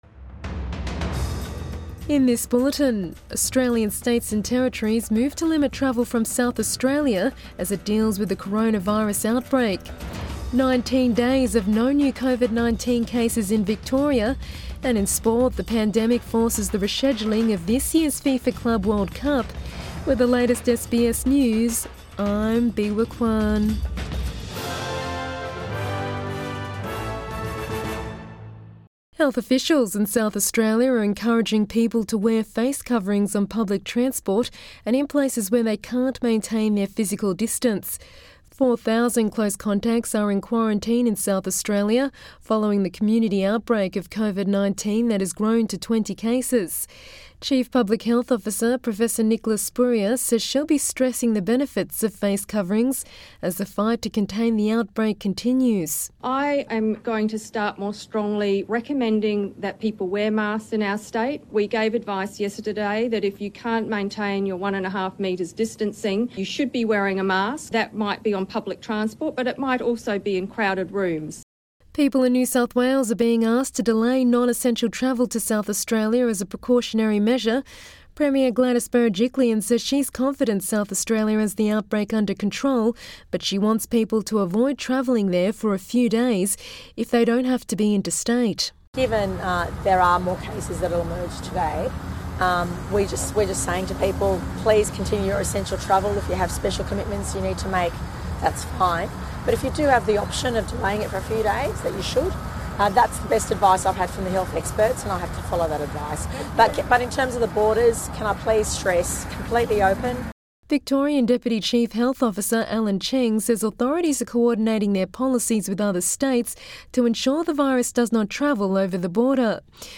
Midday bulletin 18 November 2020